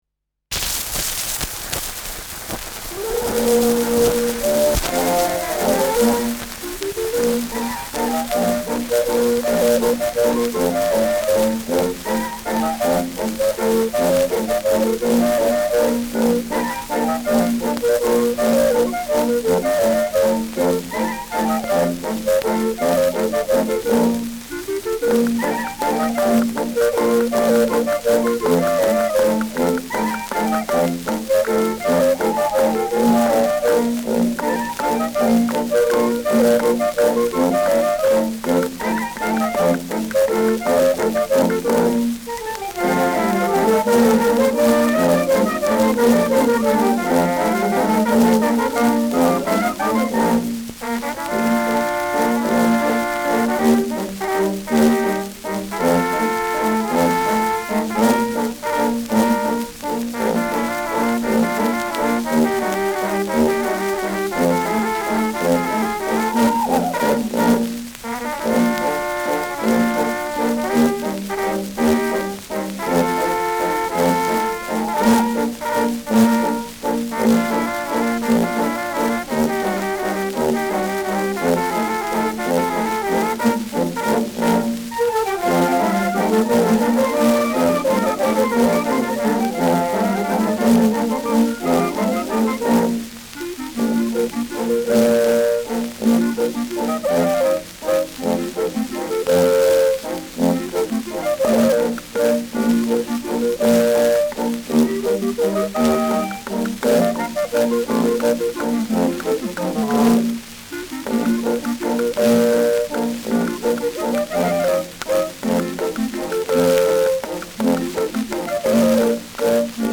Schellackplatte
Tonnadel „rutscht“ zu Beginn über einige Rillen : präsentes Rauschen : präsentes Knistern : abgespielt : gelegentliches Schnarren : Knacken bei 2’00’’
Kapelle Peuppus, München (Interpretation)
Mit Juchzern und Klopfgeräuschen.
[München] (Aufnahmeort)